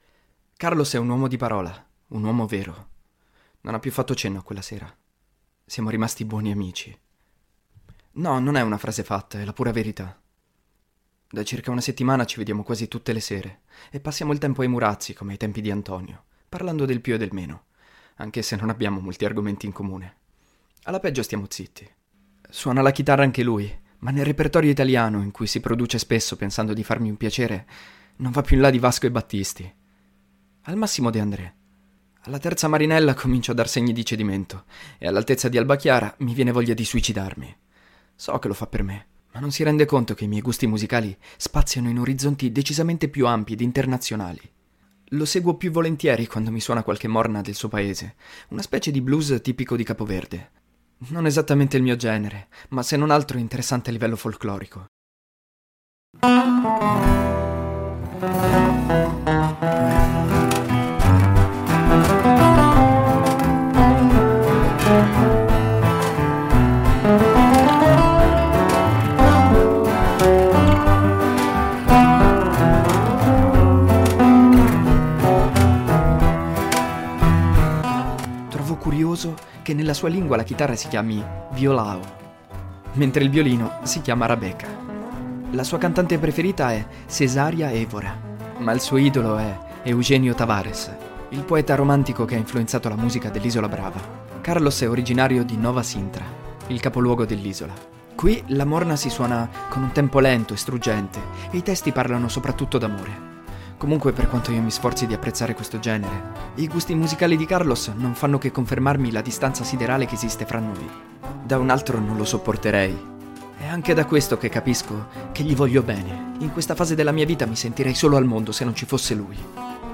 2.20. Maschio Alfa (La notte delle stelle cadenti) - RIMASTERIZZATO (Emmanuel - The broken diary (Podcast Novel))
Nel corso dell'episodio si possono ascoltare live dei seguenti brani:
During the episode you can listen to the following songs live: